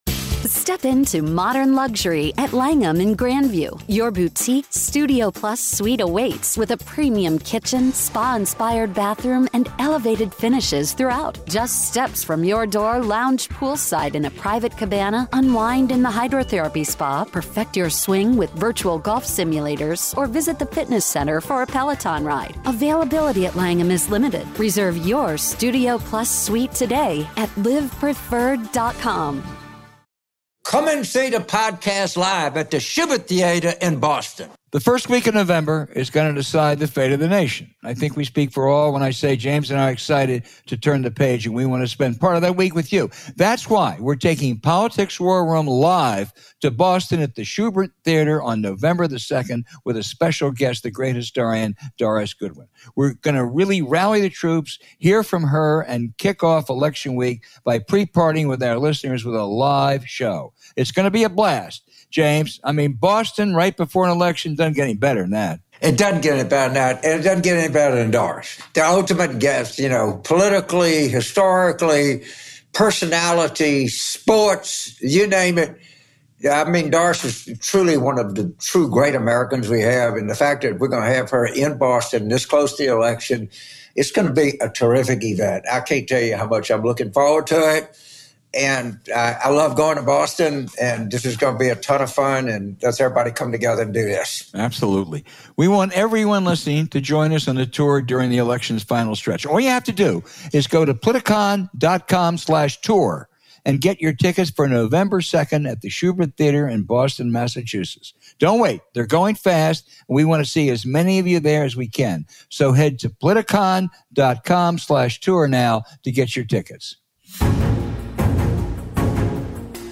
James and Al react to the VP debate, call on Kamala’s campaign to go on the offensive, and welcome Wisconsin Democratic Party Chair Ben Wikler. They strategize about how to win his state, defend the Blue Wall of northern battleground states, and expose the disastrous impact of Trump’s proposed tariffs on workers and inflation. Then, they’re joined by tennis legend Martina Navratilova to discuss her activism on behalf of women in sports, the desire to win, her experience defecting to the US from the Soviet Bloc, and the European perspective on the Ukraine War.